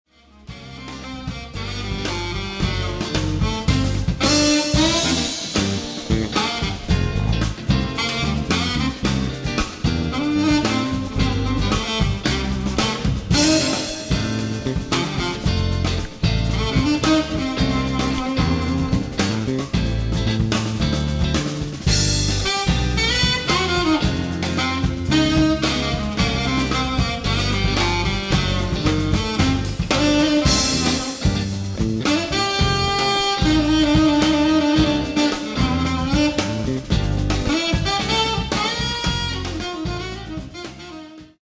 all saxophones
keyboards, programming, trumpet
bass, rhythm guitar
drums
flute
vocals
percussion